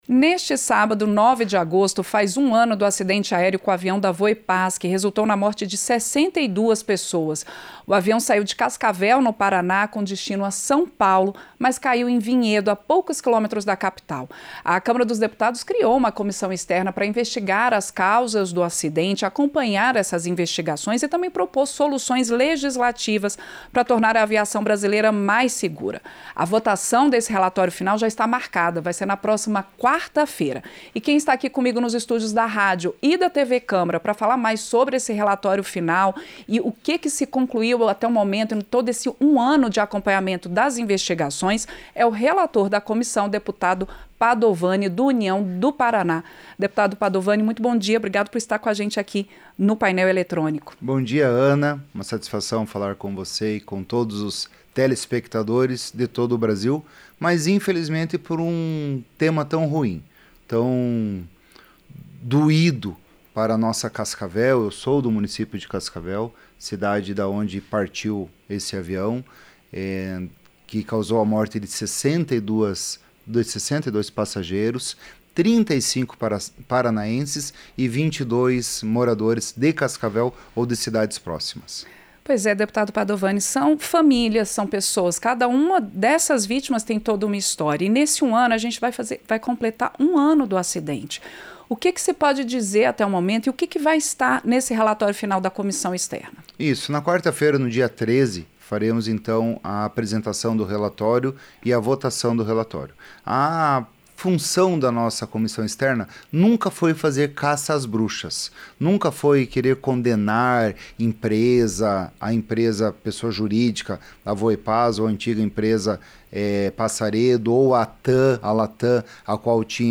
Entrevista - Dep. Padovani (União-PR)